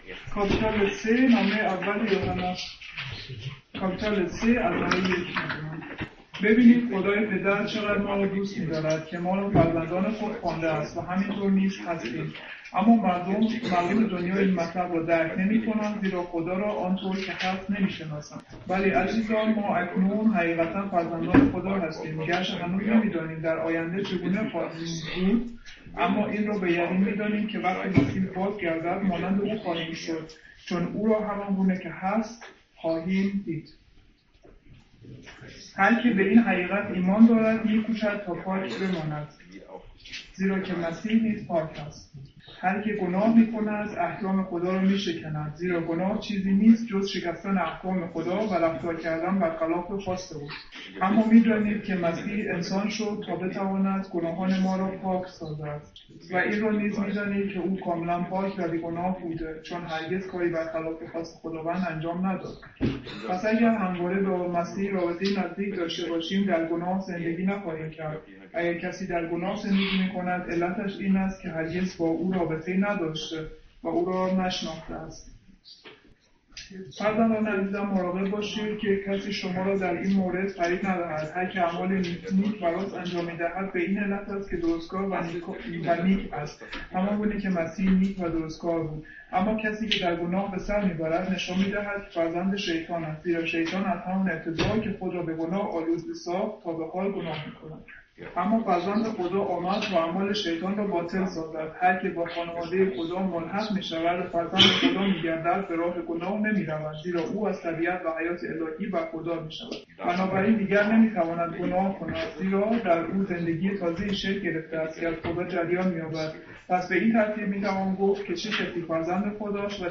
Johannes 3,1-10 | Übersetzung in Farsi